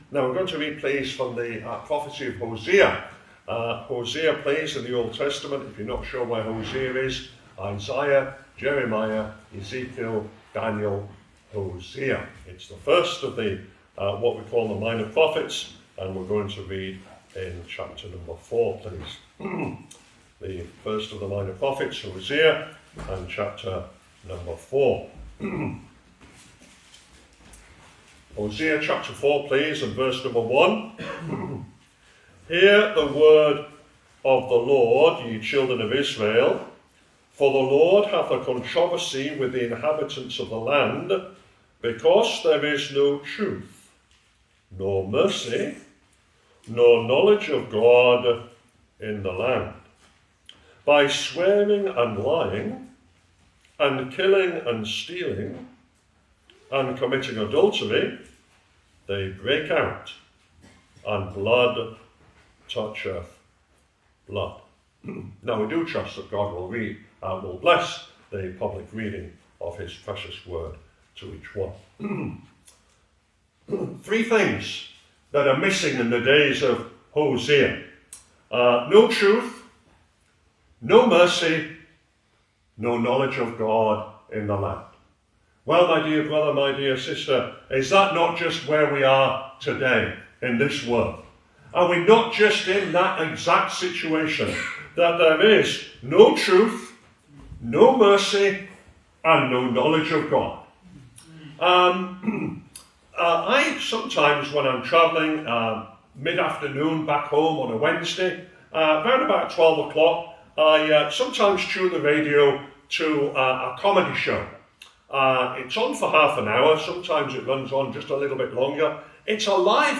Location: Cooroy Gospel Hall (Cooroy, QLD, Australia)
Missionary Report